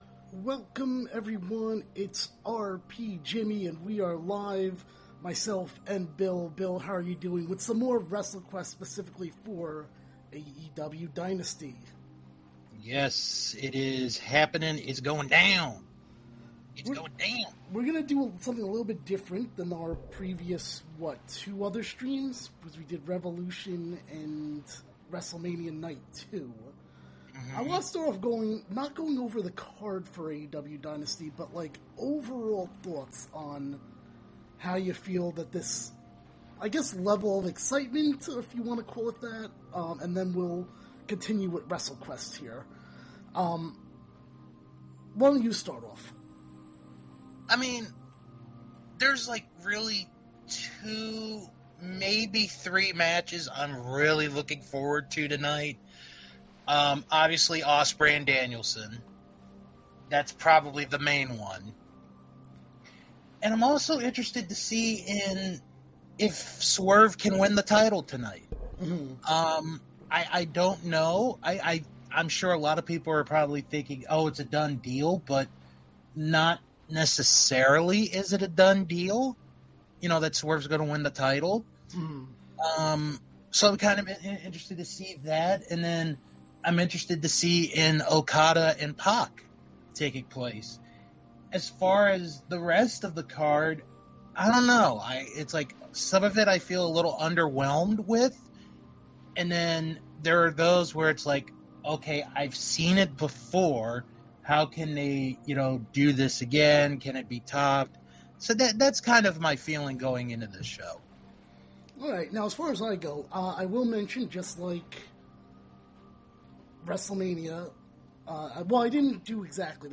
This episode was first live on Twitch before AEW Dynasty, plus they talk other wrestling topics and play some more Wrestlequest.